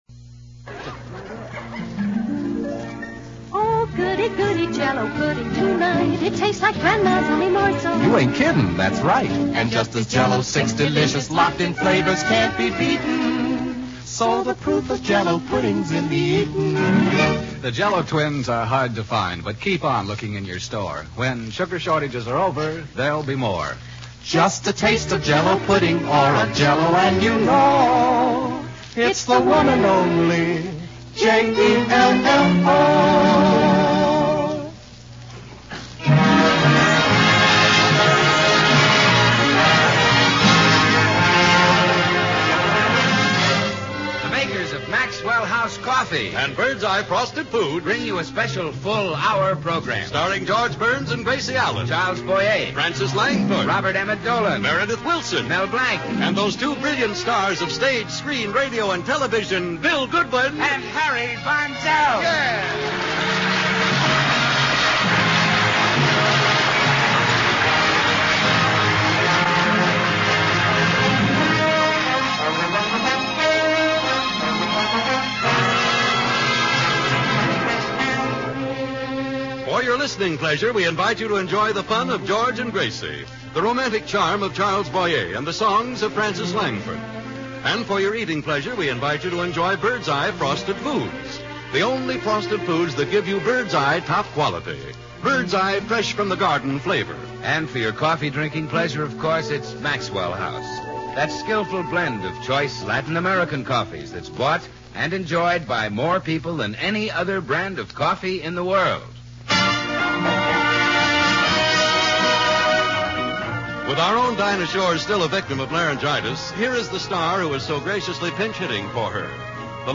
Special One Hour Broadcast